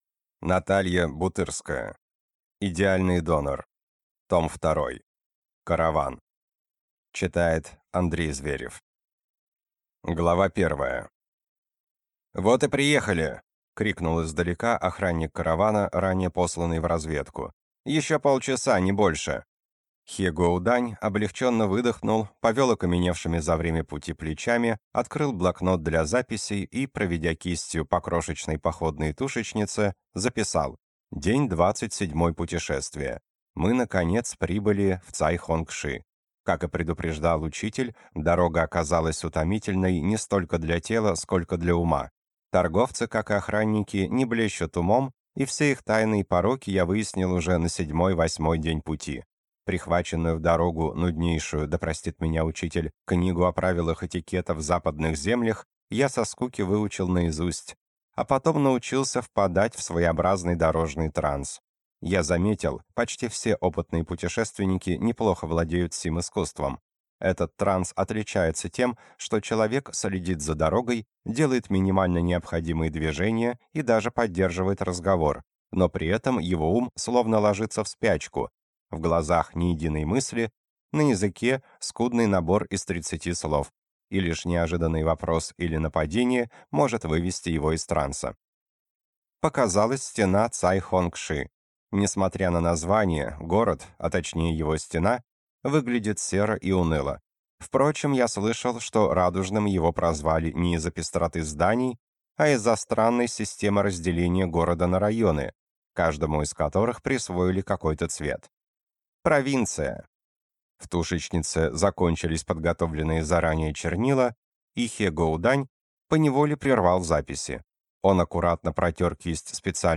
Аудиокнига Идеальный донор. Караван | Библиотека аудиокниг